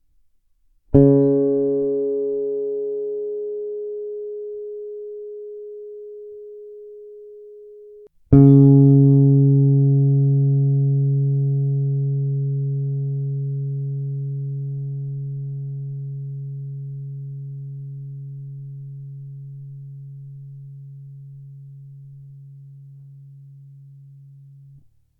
Ten při jeho držení měl velmi krátký sustain, prakticky za pár sekund umřel. Ovšem ten samý tón na 13. pražci na struně D byl dlouhej jak kráva:
Vlk na G na 8. pražci vs. ten samý tón na D na 13. pražci